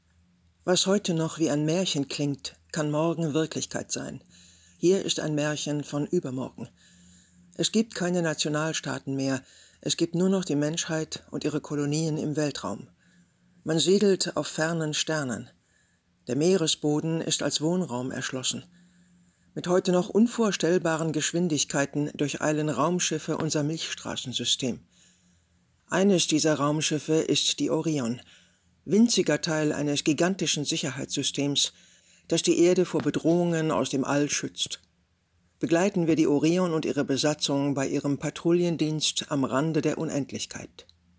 markant
Alt (50-80)
Off, Presentation